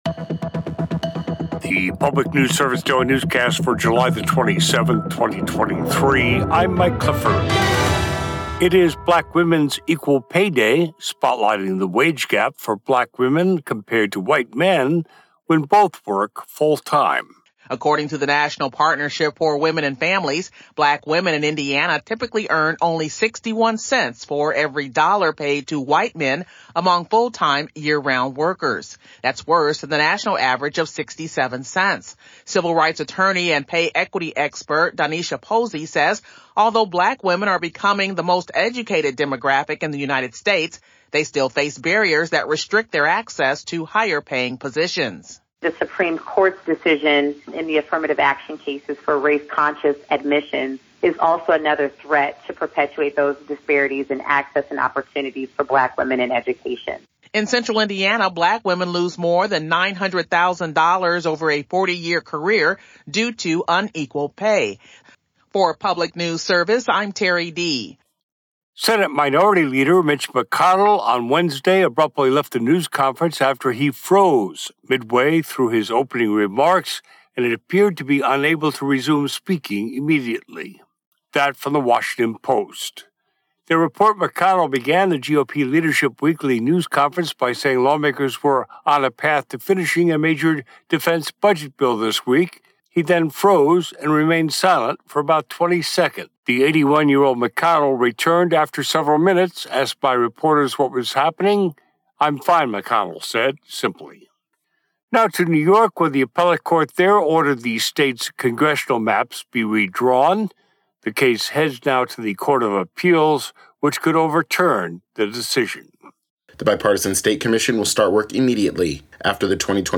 News update for Thursday, July 27, 2023